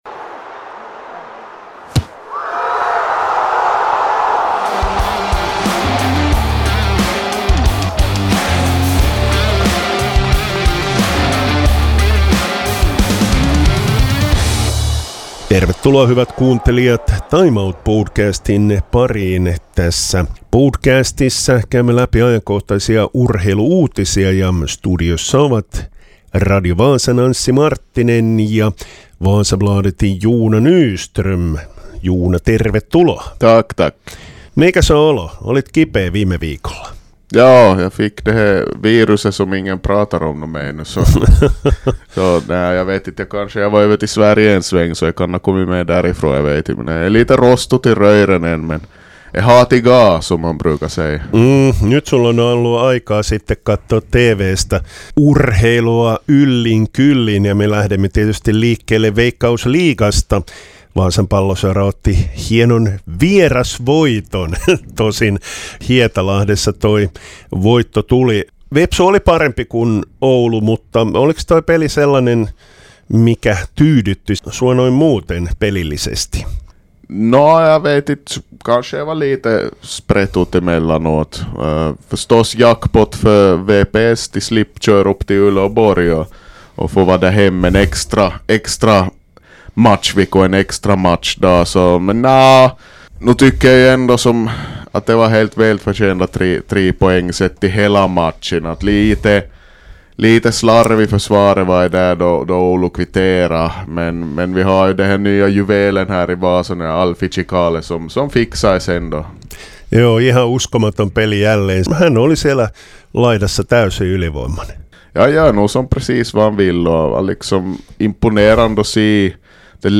I studion